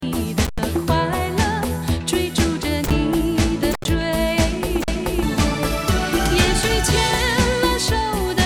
这是人家所反馈出来你的问题音乐!最好重抓轨,来搞好这次"回归"!!!!!!!!!!!!!